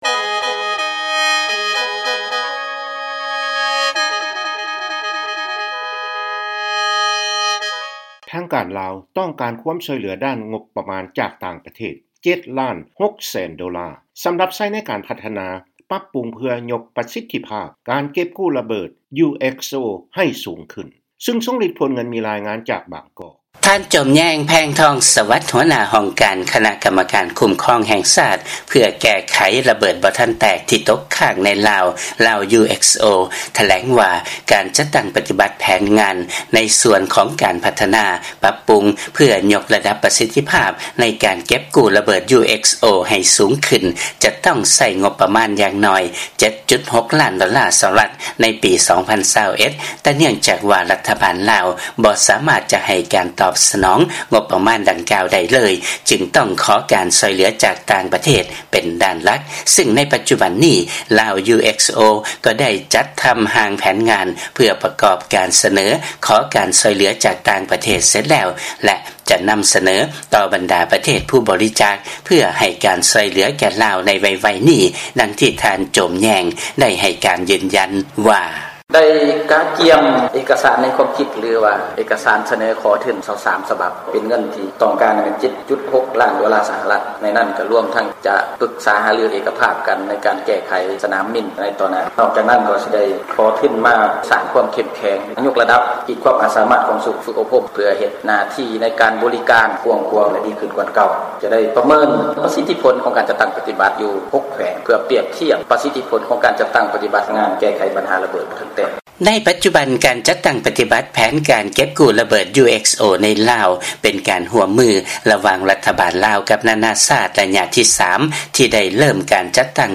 ຟັງລາຍງານ ທາງການ ລາວ ຕ້ອງການຄວາມຊ່ວຍເຫຼືອ 7.6 ລ້ານໂດລາ ຈາກຕ່າງປະເທດ ສຳລັບນຳໃຊ້ໃນໂຄງການ ເກັບກູ້ລະເບີດ UXO